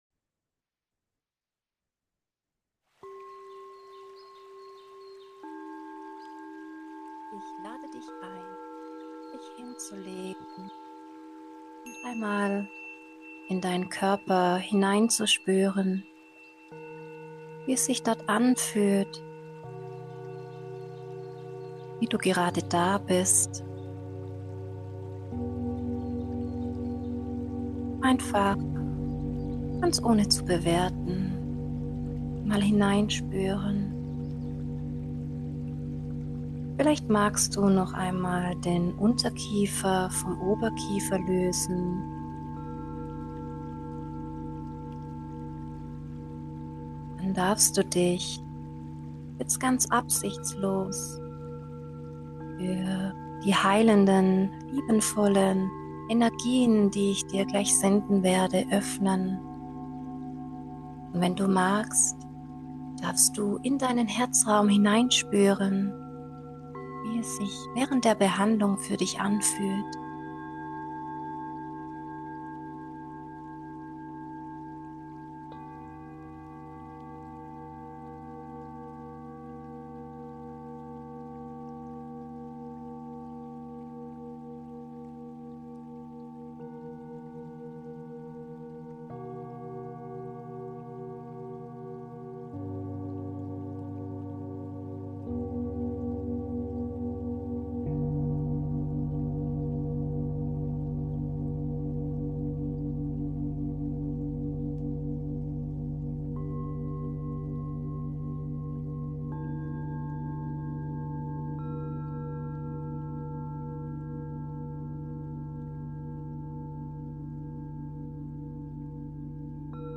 Die Datei Klangreise-sich-selbst-annehmen.mp3 herunterladen
Klangreise – Sich selbst annehmen: Eine energetische Klangreise, deren Fokus darauf ruht, Dich in die Begegnung mit Dir selbst zu führen und Selbstannahme auf zellulärer Ebene zu verankern.